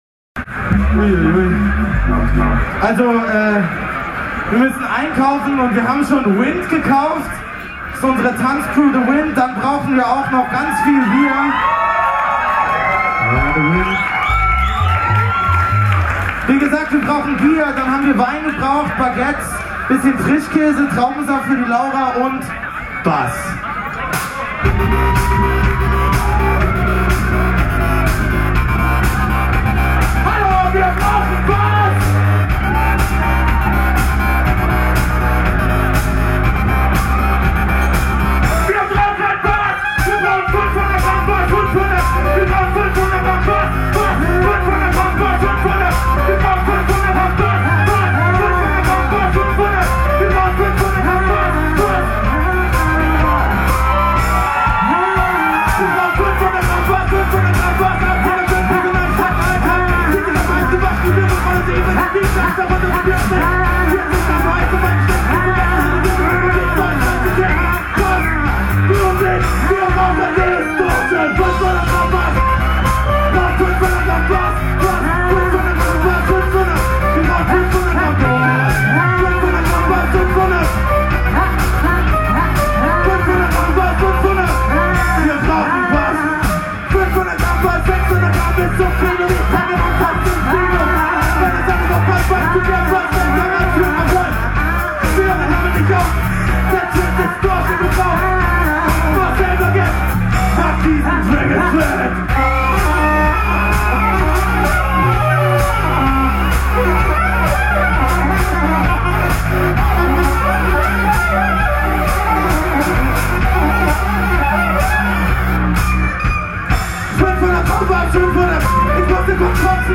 die Menge bei "das Fest" 2009 zum Kochen